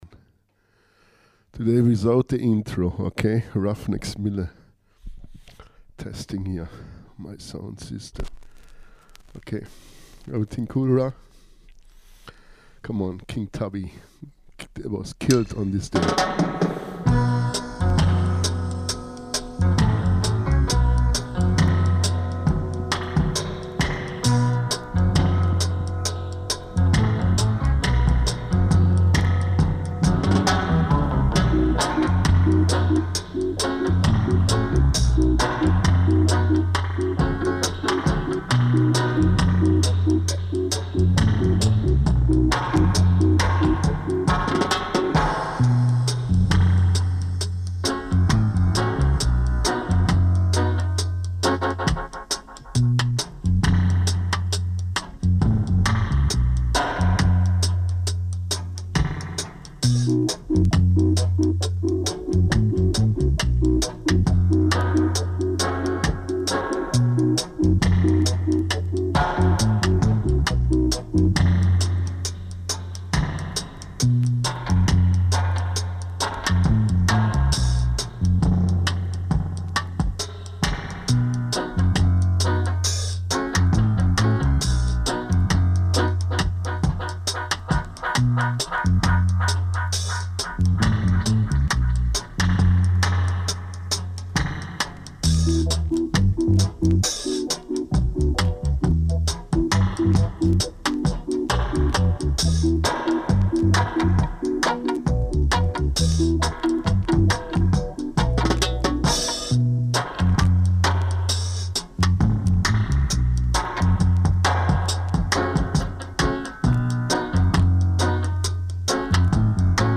spiced with Dubs
Strictly Vinyl Vibz